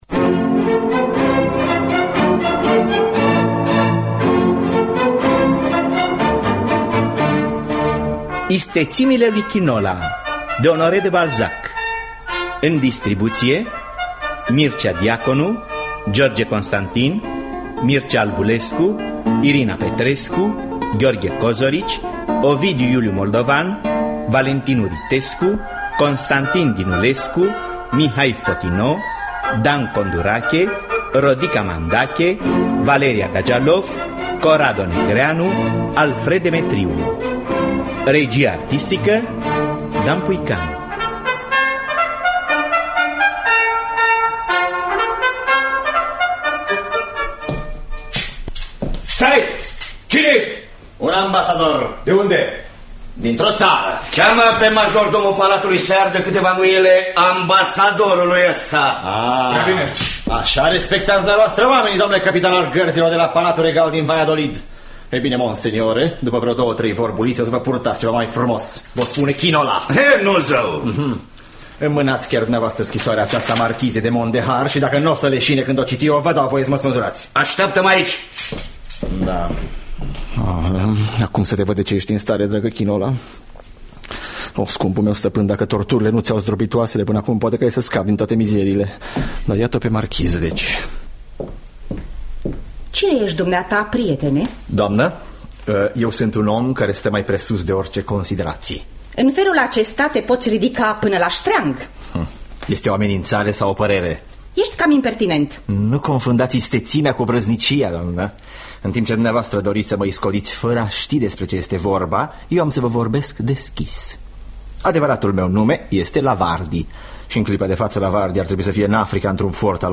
Istețimile lui Quinola de Honoré de Balzac – Teatru Radiofonic Online